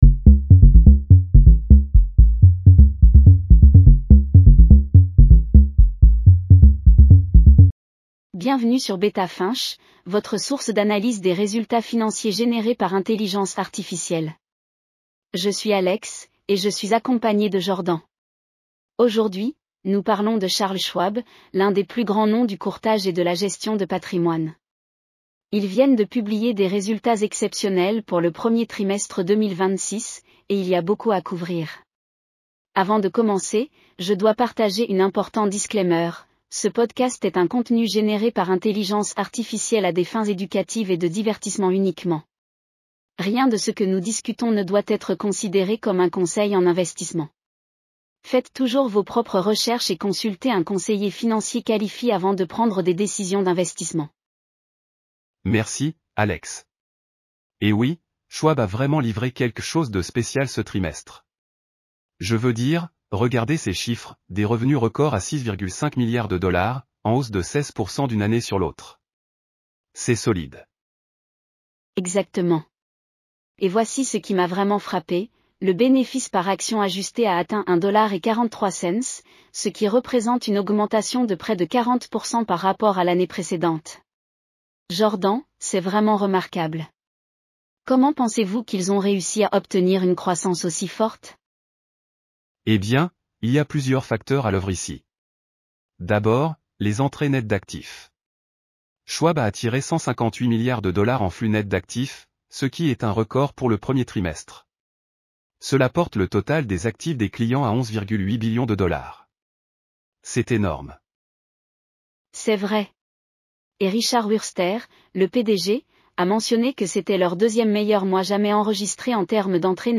Charles Schwab Q1 2026 earnings call breakdown. Full transcript & podcast. 12 min. 5 languages.